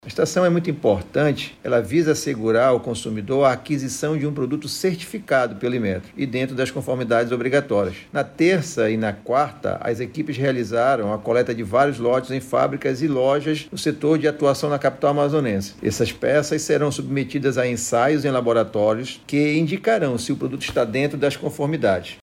O diretor-presidente do Ipem Amazonas, Renato Marinho, destacou a importância das fiscalizações para proteger o consumidor de adquirir produtos que não estejam dentro dos padrões estabelecidos em Lei.